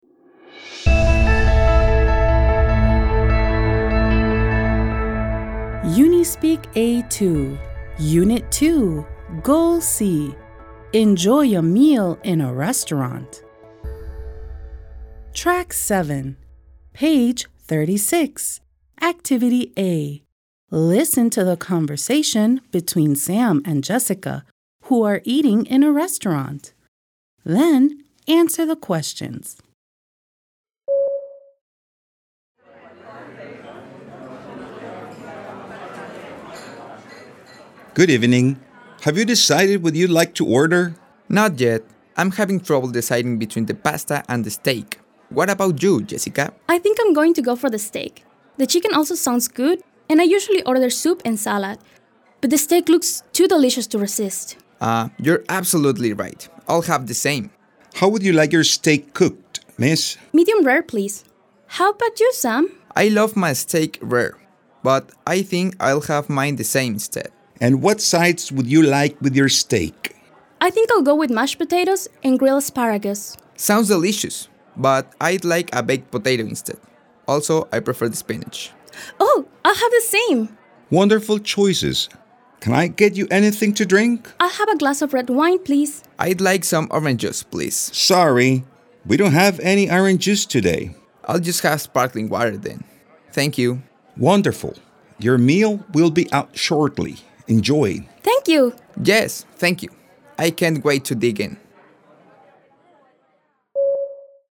Listen to the conversation between Sam and Jessica who are eating in a restaurant.